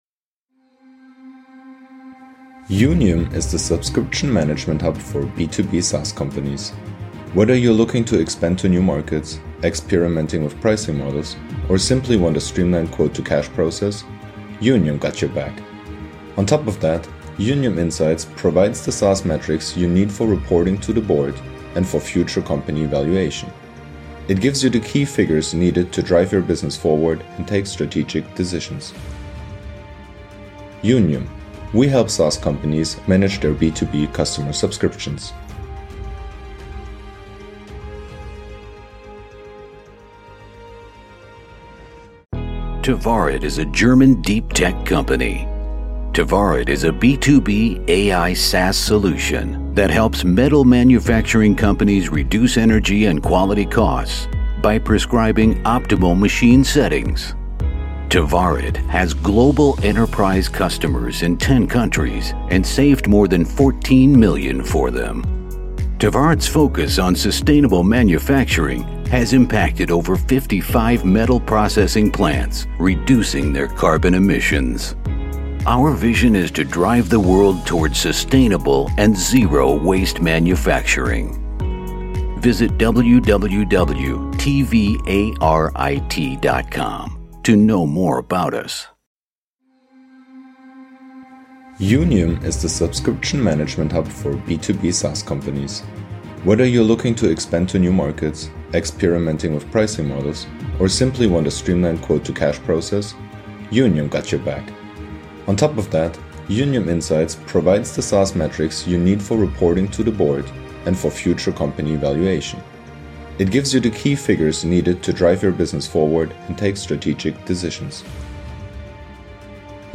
We recorded this news episode on Thursday, October 20th, 2022.